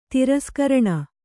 ♪ tiraskaraṇa